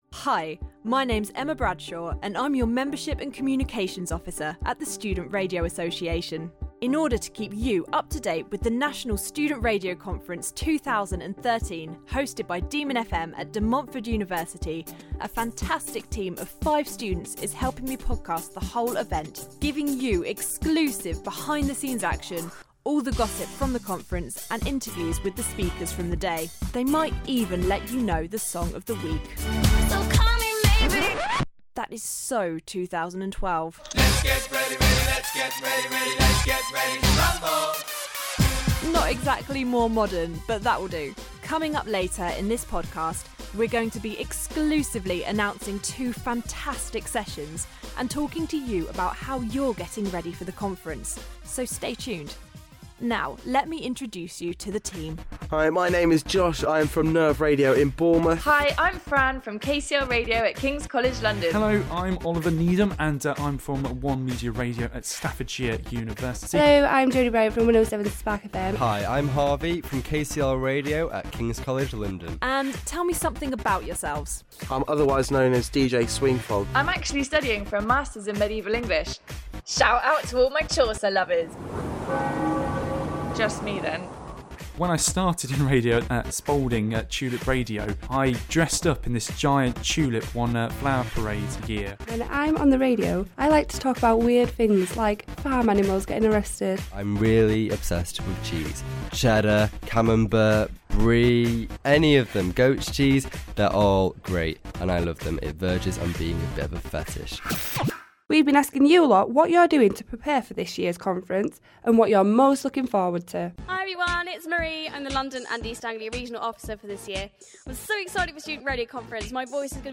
This is the first podcast from the newly created podcast team for the National Student Radio Conference 2013 from Demon FM in Leicester. Here we introduce the team and speak to some students about how they're preparing for the week.